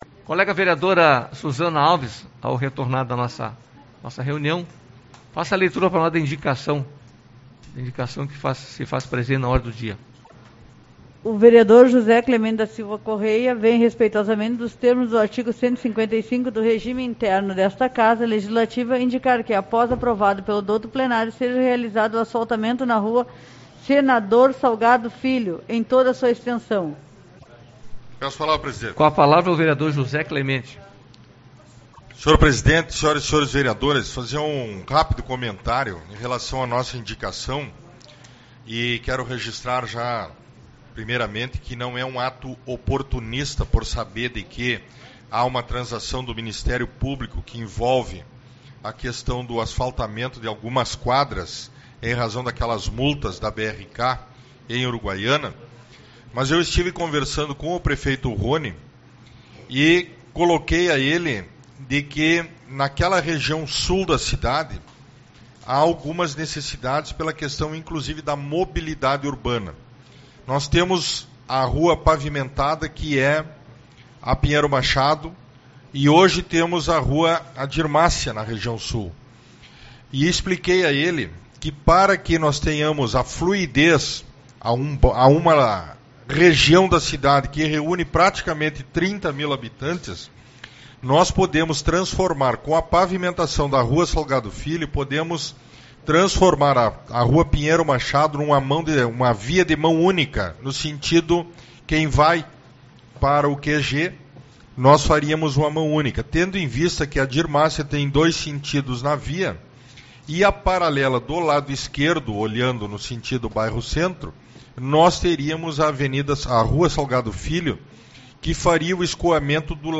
12/11 - Reunião Ordinária